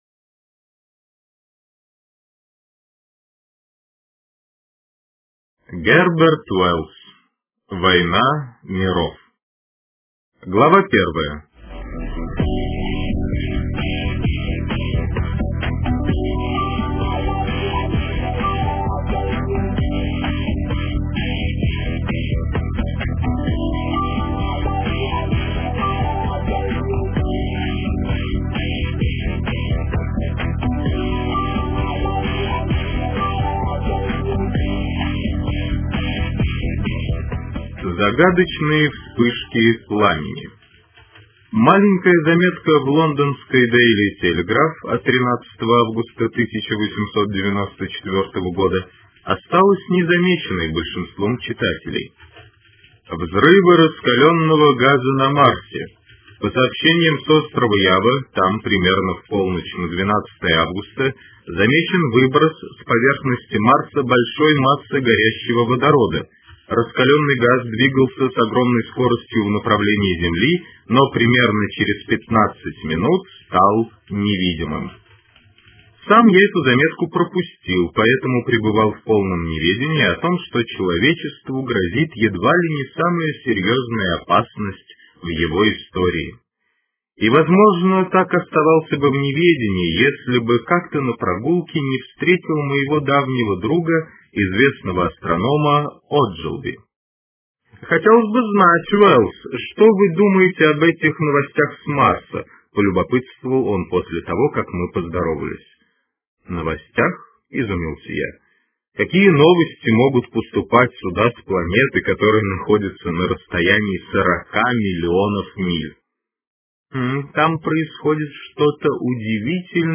[New!] Глава 1 аудио книги Г. Уэллса "Война миров" 377kb